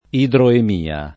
idroemia [ idroem & a ]